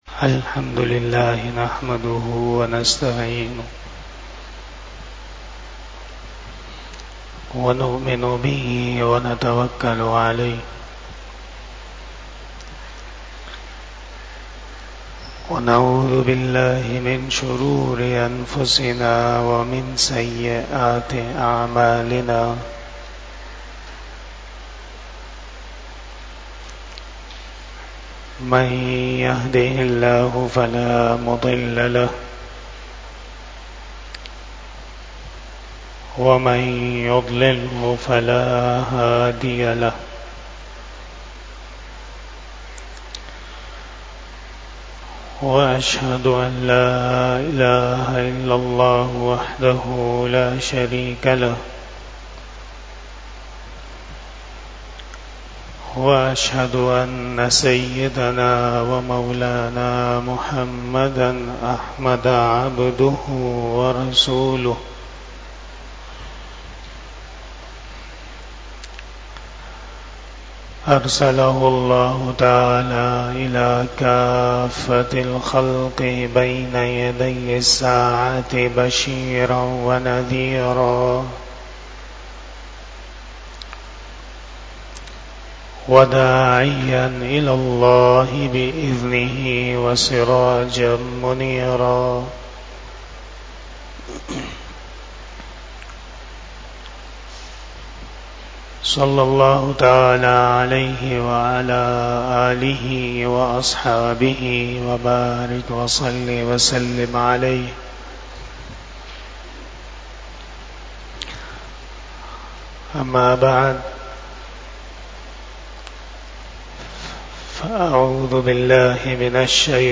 28 Bayan E Jummah 12 July 2024 (05 Muharram 1446 HJ)